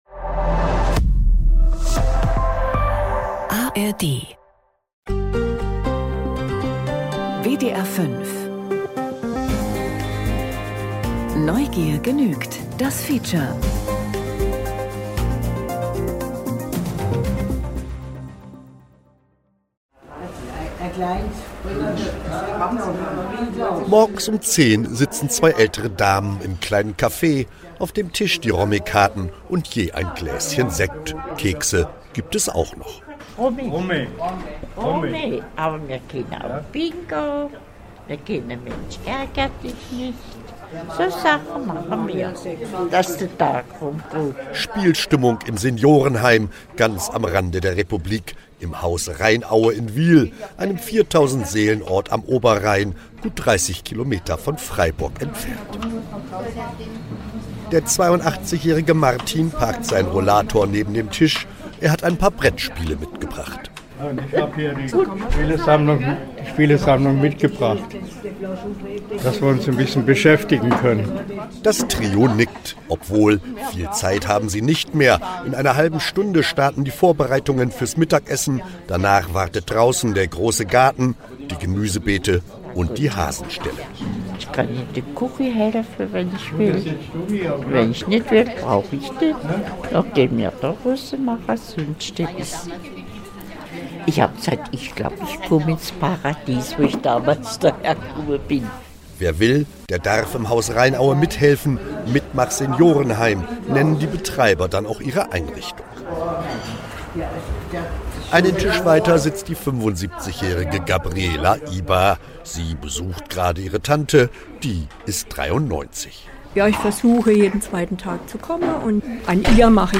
WDR 5 Neugier genügt – das Feature, 14.08.2025